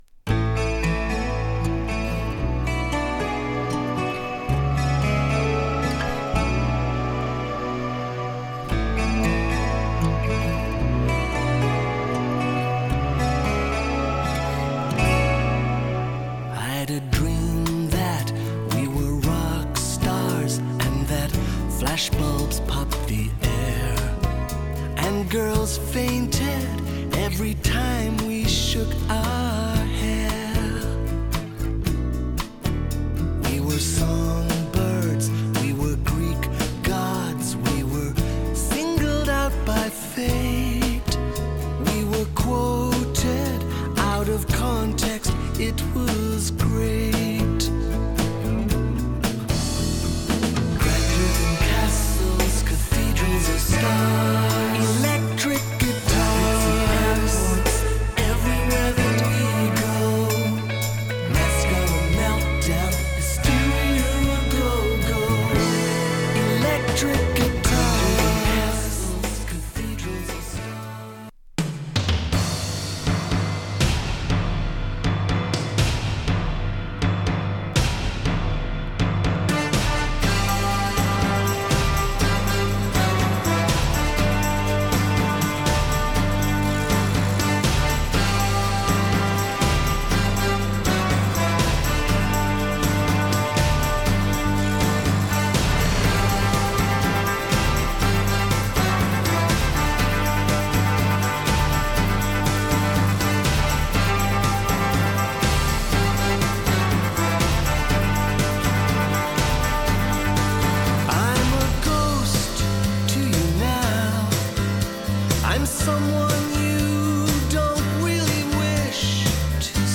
満天の星々が儚く煌めく80年代後期ネオアコ大名作。